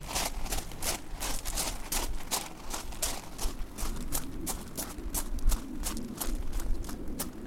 walk.wav